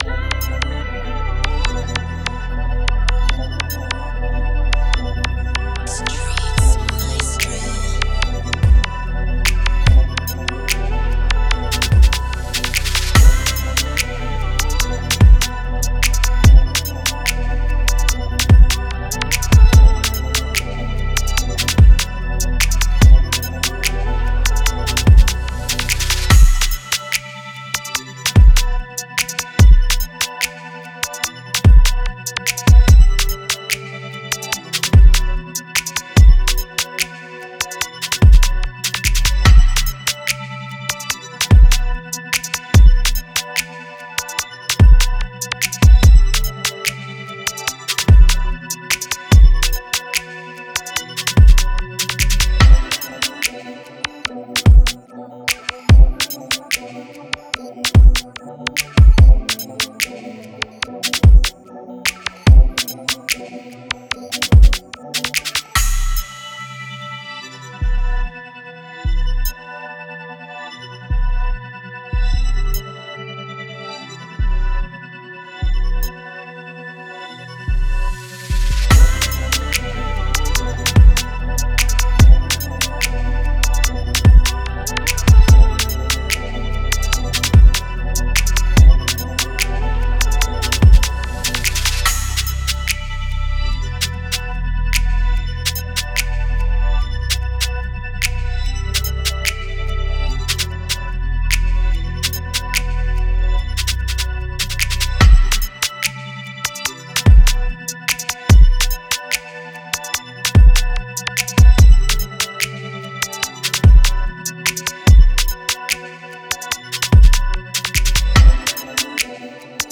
Moods: bouncy, laid back, mellow
Genre: Sexy Drill
Tempo: 146